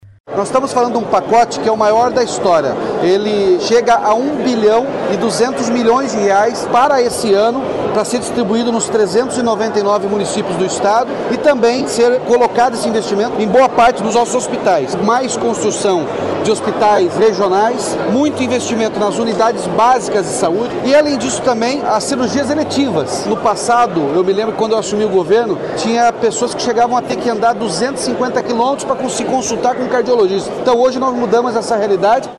O governador Carlos Massa Ratinho Junior anunciou nesta quinta-feira (13) a liberação de R$ 1,2 bilhão para a saúde pública do Estado, durante o evento Saúde em Movimento, em Foz do Iguaçu, na região Oeste.
Player Ouça Governador Ratinho Júnior